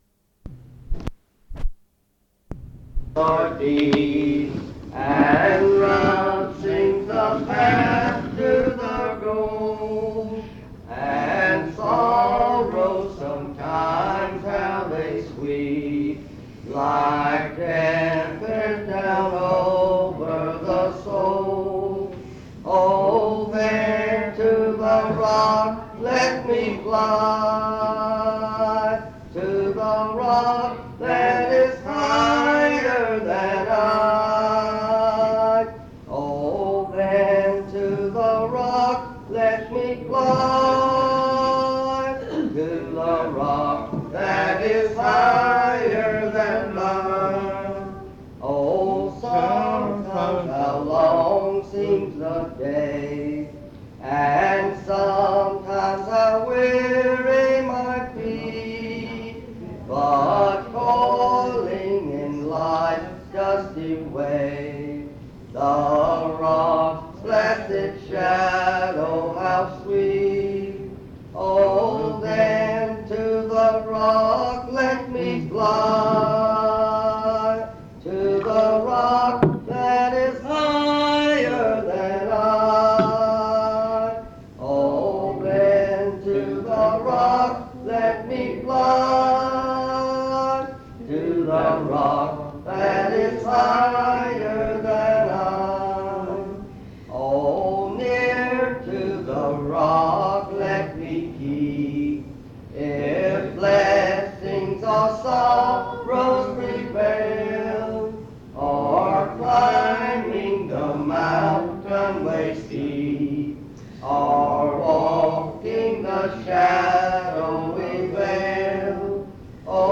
Primitive Baptists